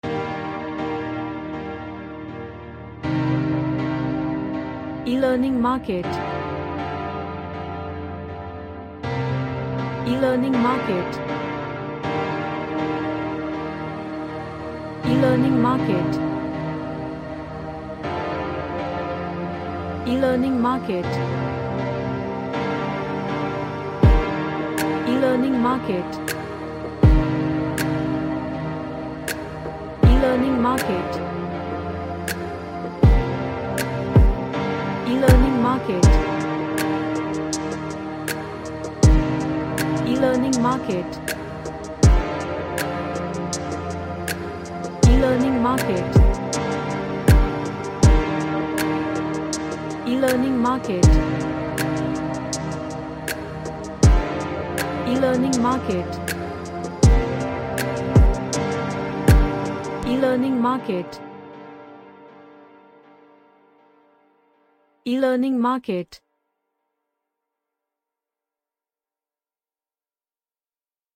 A relaxing nebula featured track.
Relaxation / Meditation